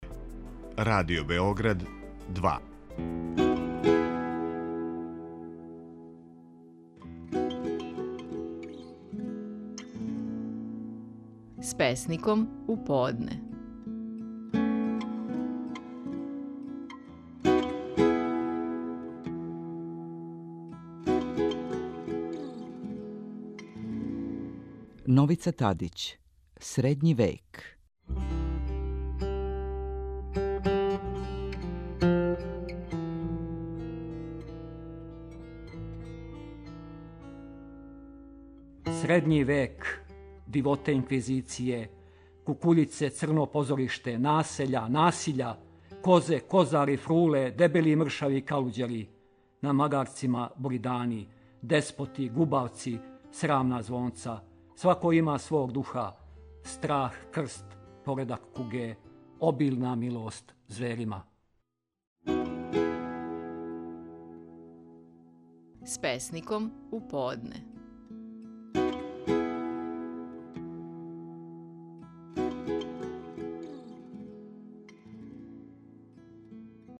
Стихови наших најпознатијих песника, у интерпретацији аутора.
Новица Тадић говори песму „Средњи век".